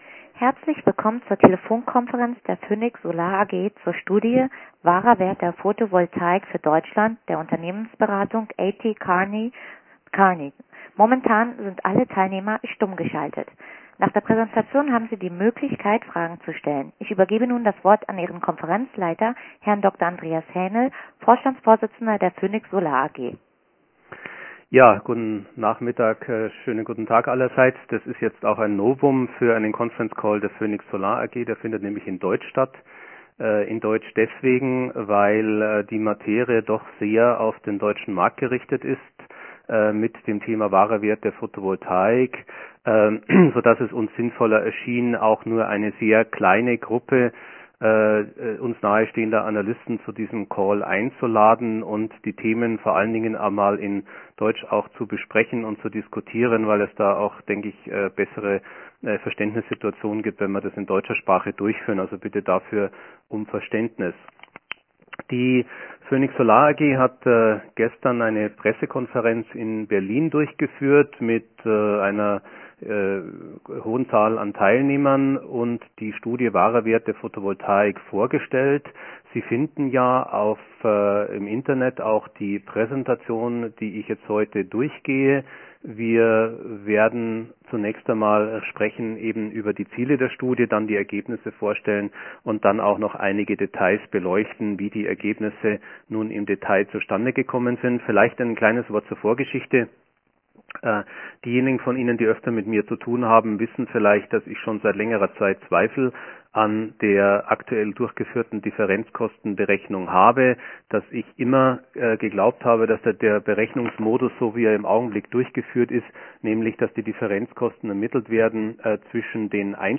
Analystenkonferenz